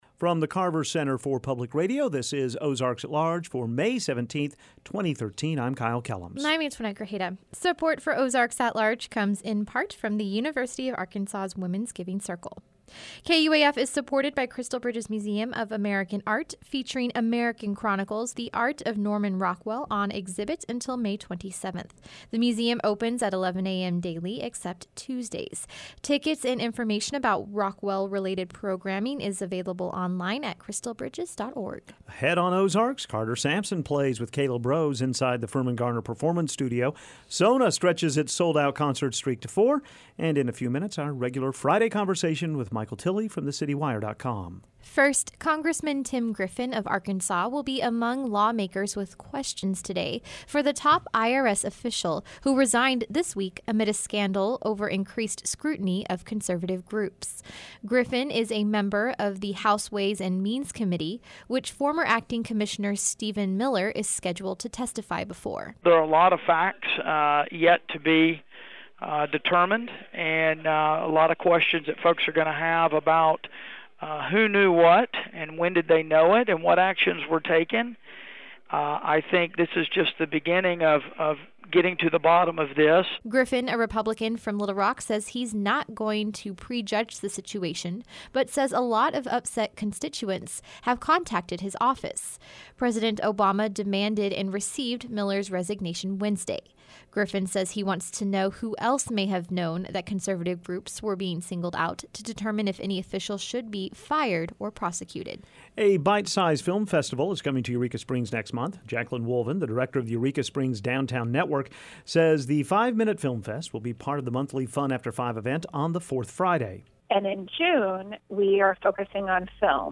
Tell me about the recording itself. inside the Firmin-Garner Performance Studio